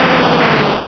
-Replaced the Gen. 1 to 3 cries with BW2 rips.